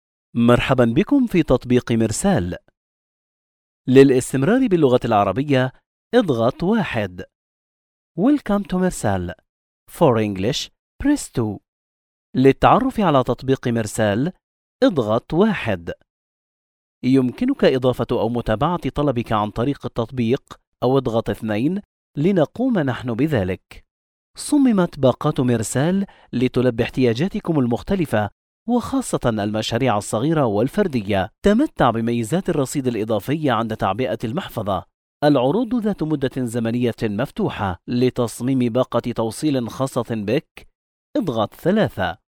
IVR
Adulto joven
Mediana edad